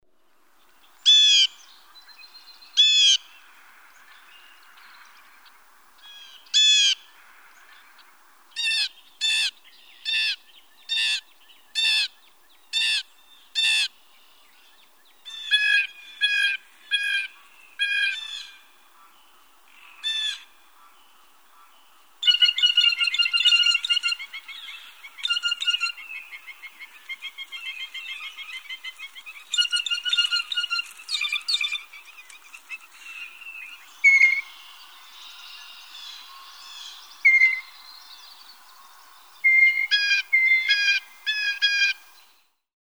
Blue jay
A variety of typical calls, including harsh jays, "squeaky gates," and "bell" calls.
Norwottuck Rail Trail, Amherst, Massachusetts.
356_Blue_Jay.mp3